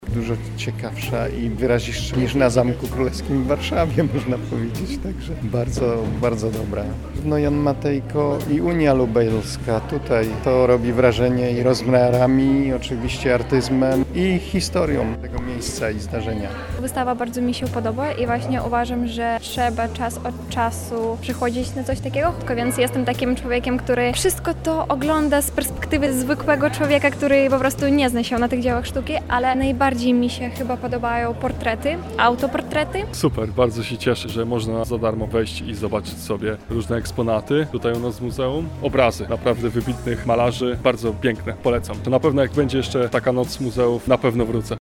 Jak wydarzenie oceniają zwiedzający?